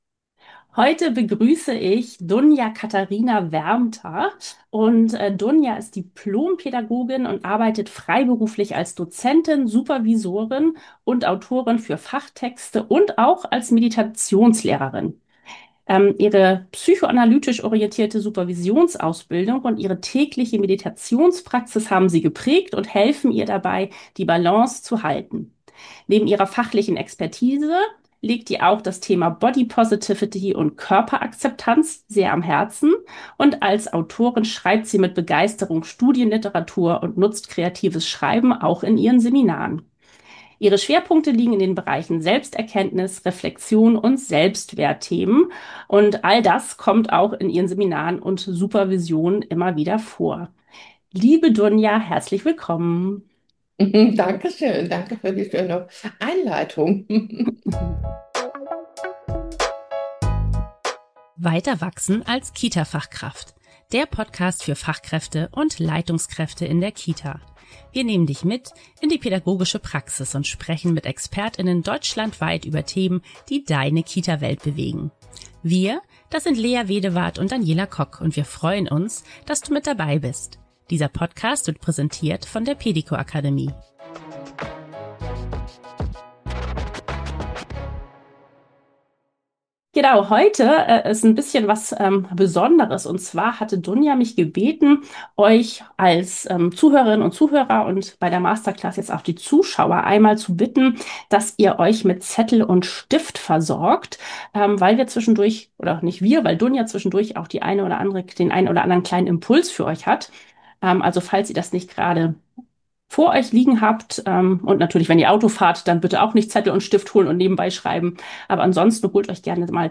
Ein Gespräch über Selbsterkenntnis, innere Kindarbeit und den Mut, genauer hinzuschauen.